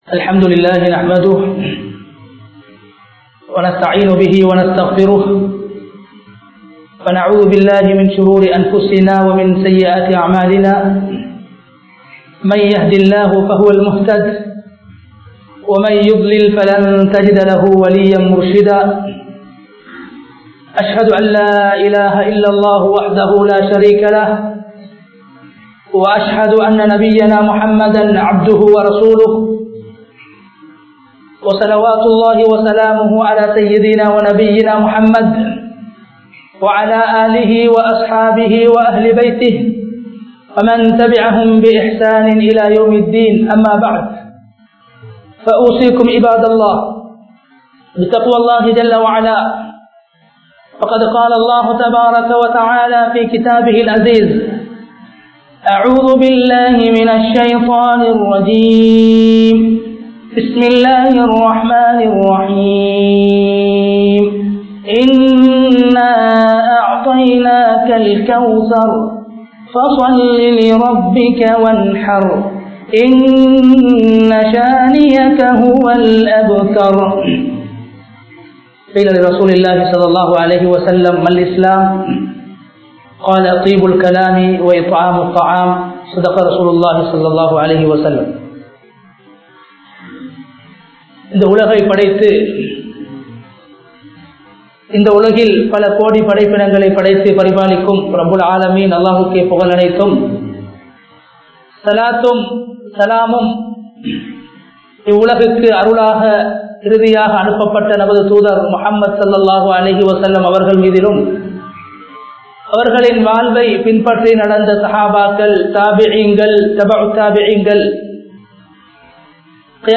இப்றாஹீம்(அலை) அவர்களின் குடும்பத்தின் 03 தியாகங்கள் ( 03 Dedications of Ibrahim (Alai) & His family) | Audio Bayans | All Ceylon Muslim Youth Community | Addalaichenai